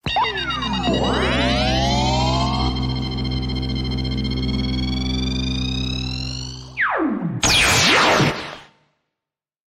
Kumas Laser Sound Effect Free Download